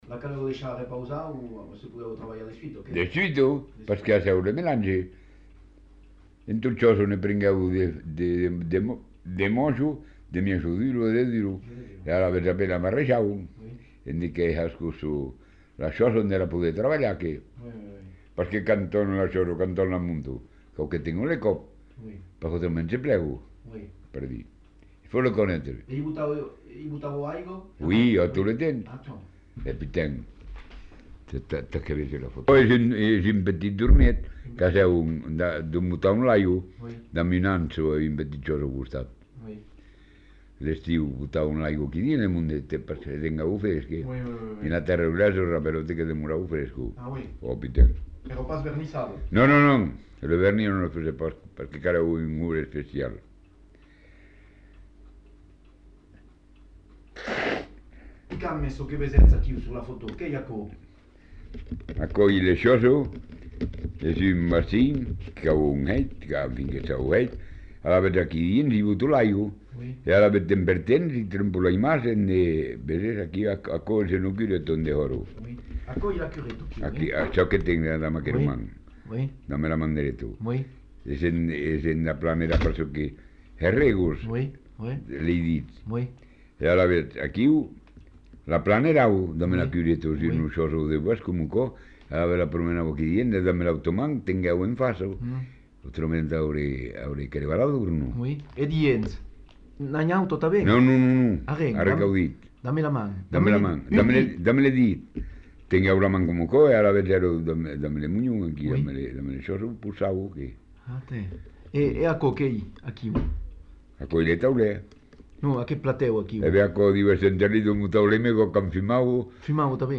Genre : témoignage thématique
Contenu dans [enquêtes sonores]